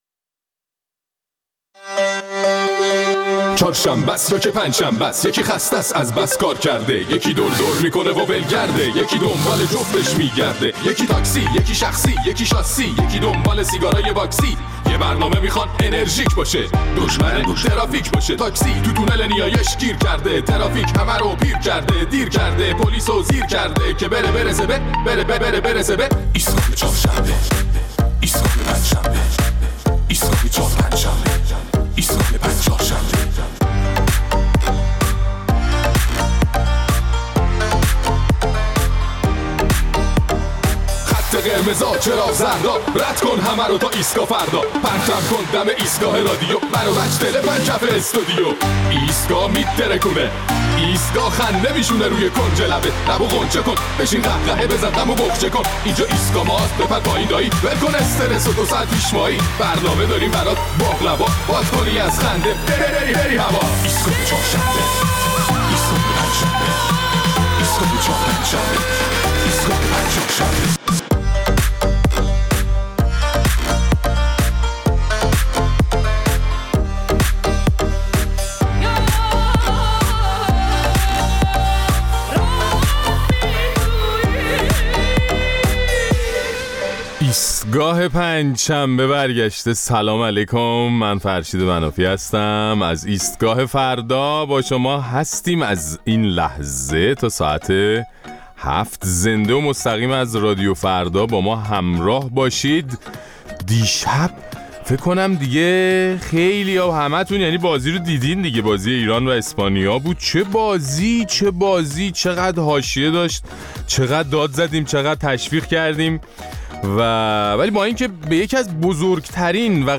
در این برنامه ادامه نظرات شما در مورد انتقاد اخیر رهبر ایران از تعداد زیاد سفرهای خارجی می‌شنویم.